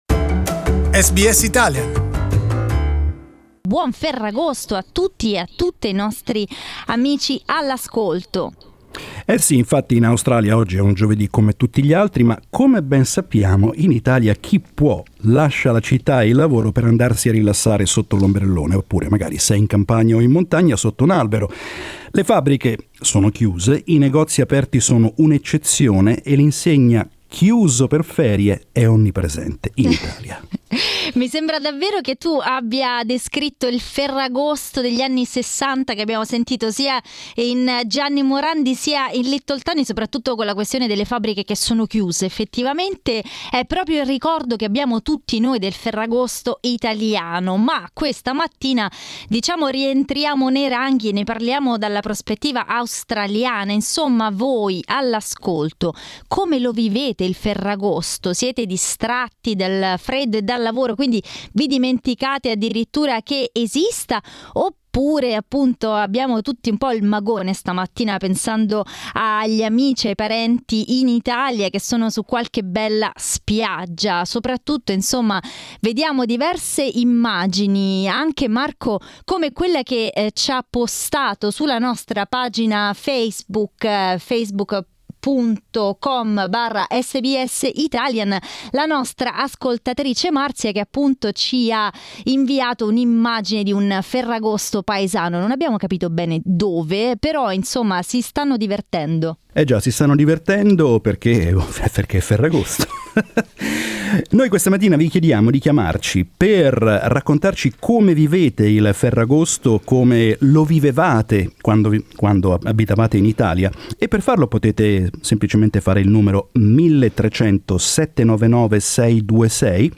We have opened the lines to talk about Ferragosto!
But how do Italians who live in Australia celebrate Ferragosto? This exactly what this morning we asked our listeners.